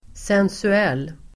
Ladda ner uttalet
Folkets service: sensuell sensuell adjektiv, sensual Uttal: [sensu'el:] Böjningar: sensuellt, sensuella Synonymer: sexig Definition: sinnlig, vällustig Exempel: en sensuell man (a sensual man) sensual , sensuell